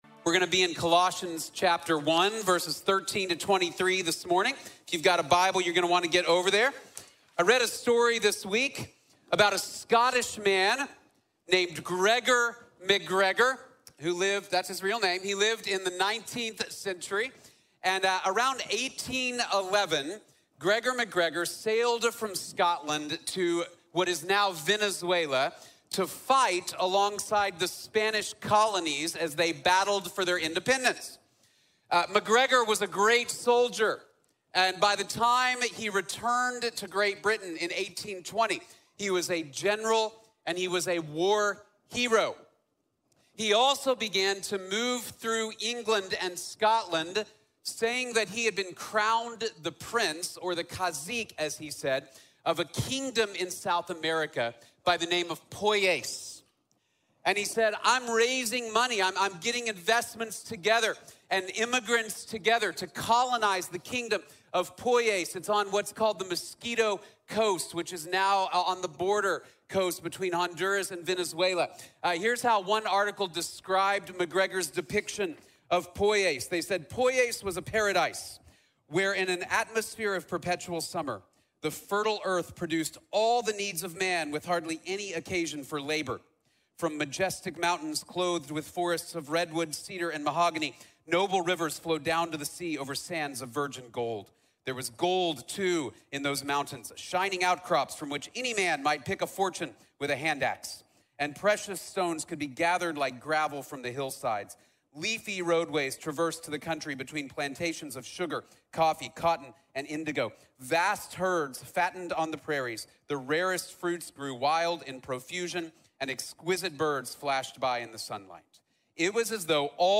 Worthy of First Place | Sermon | Grace Bible Church